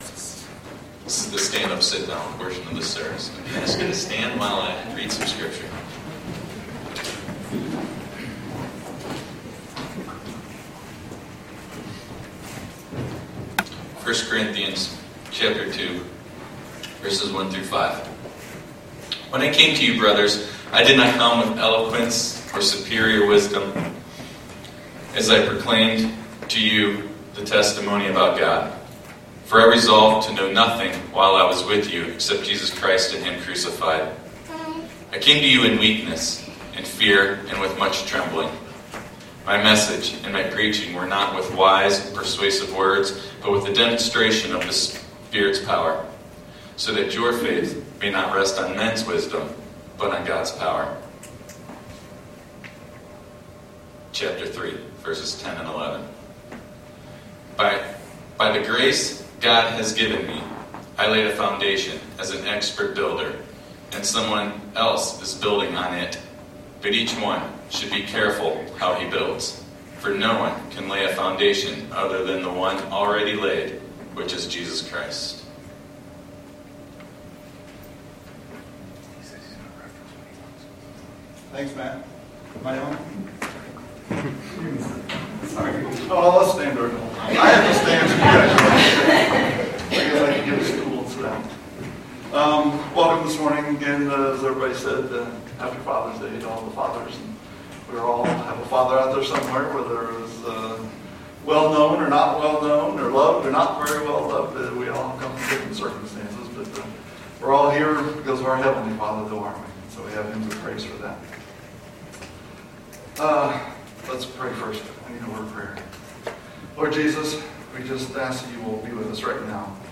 Grace Summit Community Church | Cuyahoga Falls, Ohio
Good morning – Happy Fathers’ Day!